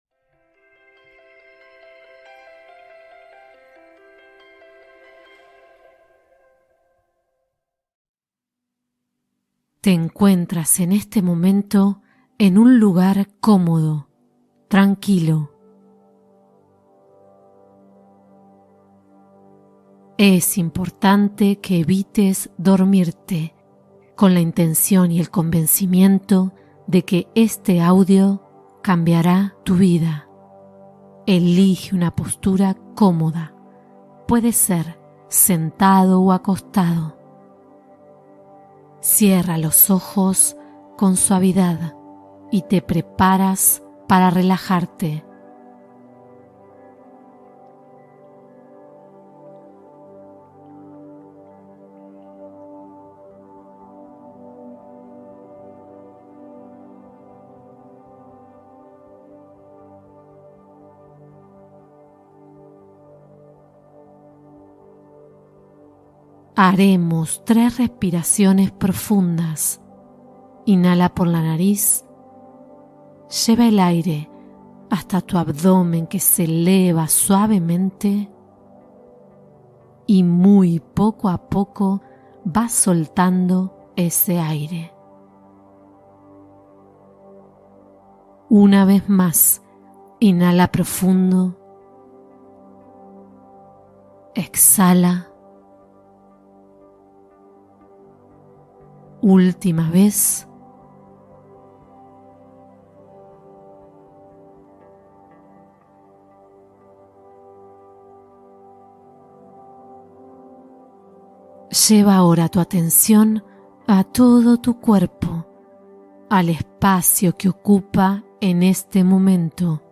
Perdona y perdónate con mindfulness: meditación para la liberación emocional